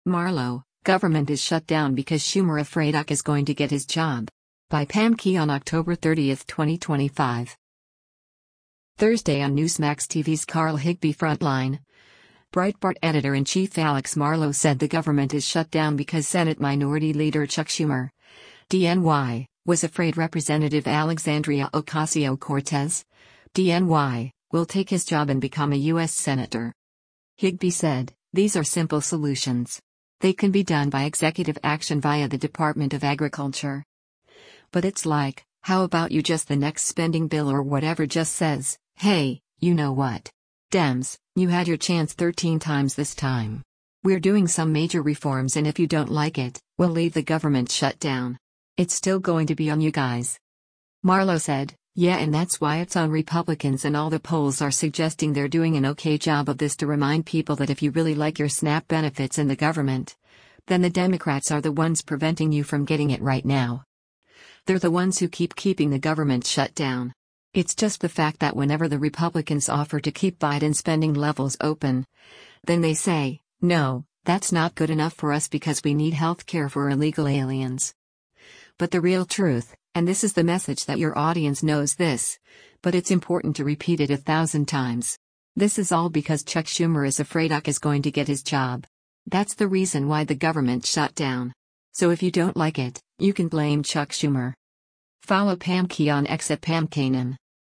Thursday on Newsmax TV’s “Carl Higbie Frontline,” Breitbart Editor-in-Chief Alex Marlow said the government is shut down because Senate Minority Leader Chuck Schumer (D-NY) was afraid Rep. Alexandria Ocasio-Cortez (D-NY) will take his job and become a U.S. Senator.